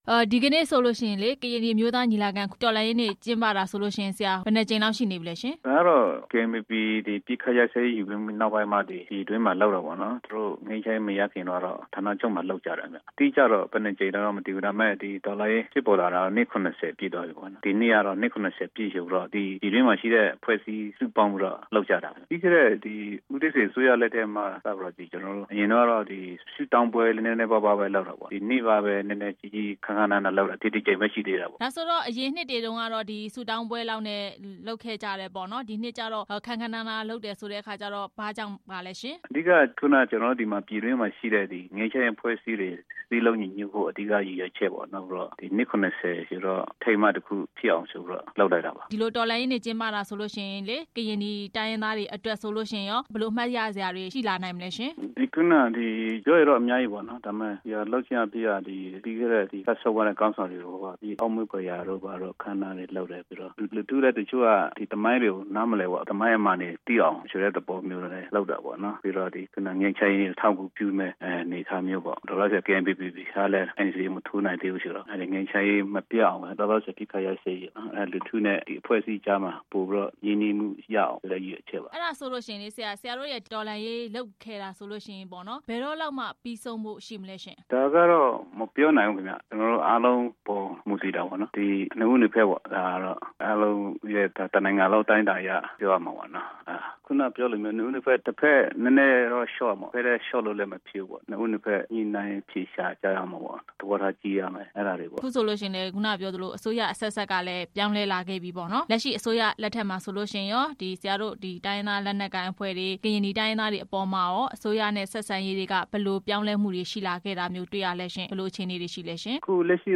မေးမြန်းခန်း